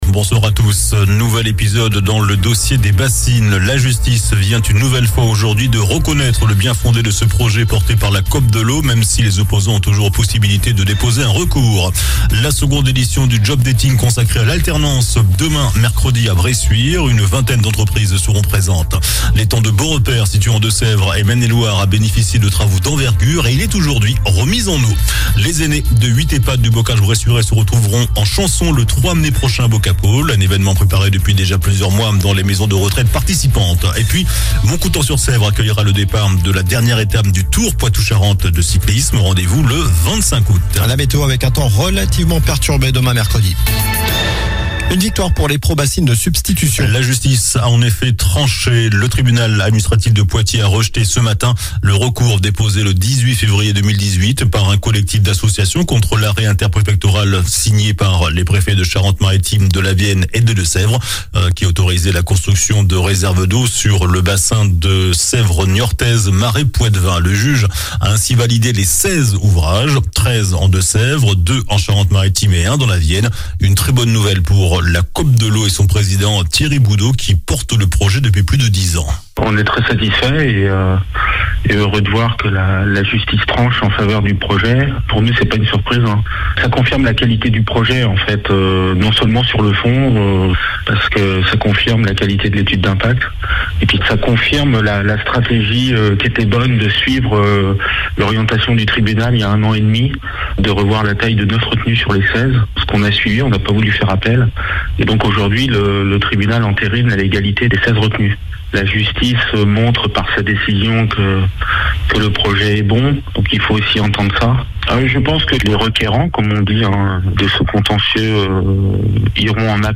JOURNAL DU MARDI 11 AVRIL ( SOIR )